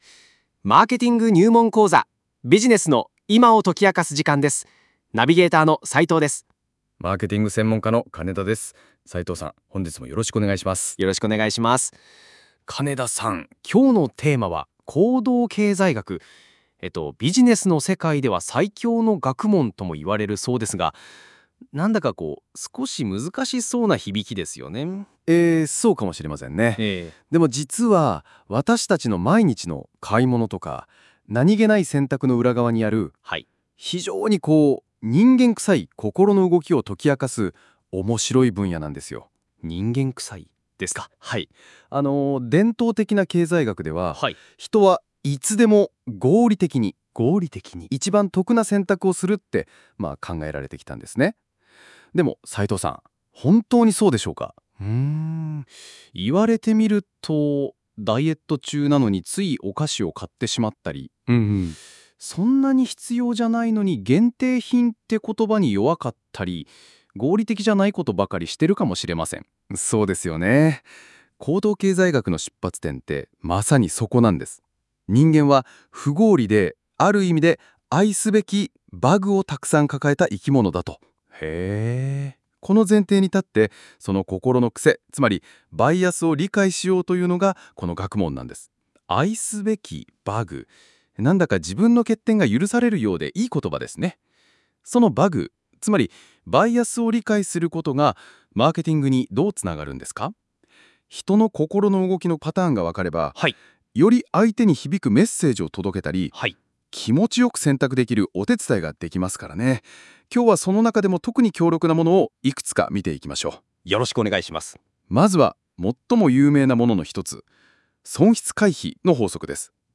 会話の文字起こしはこちら↓